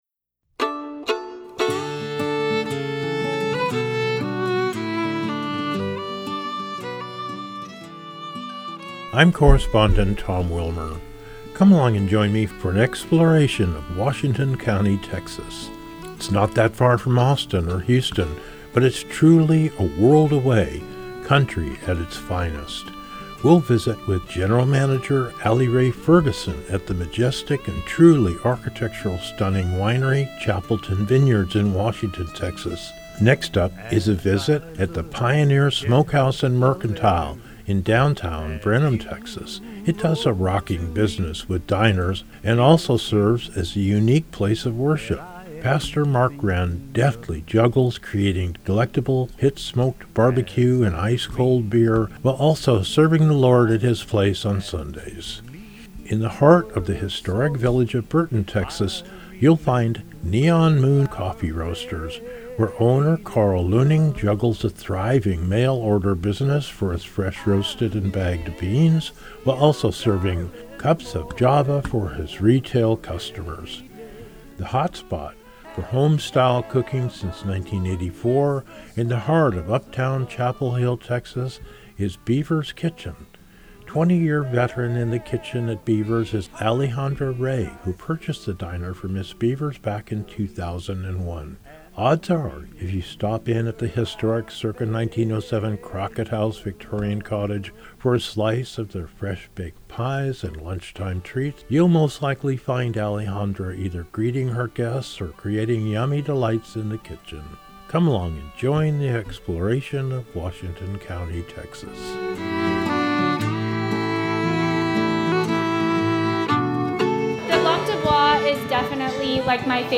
culinary-pod-brenham.mp3